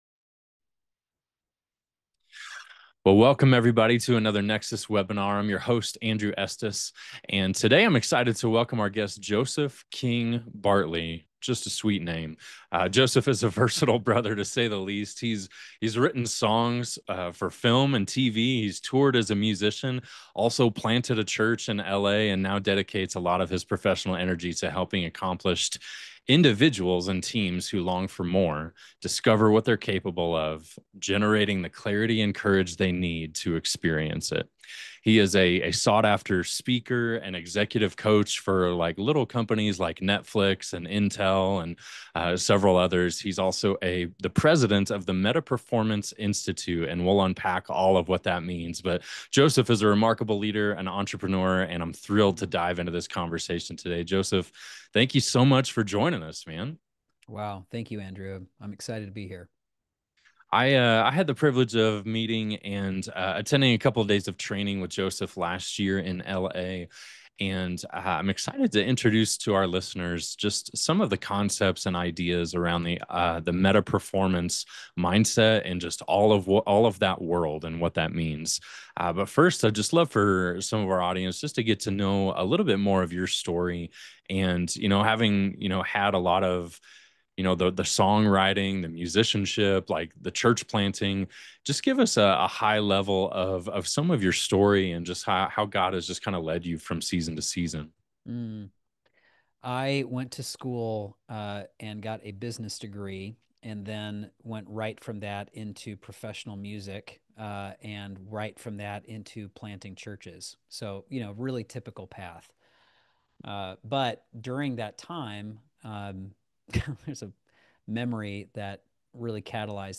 This webinar is your invitation to pause… and ask a better question.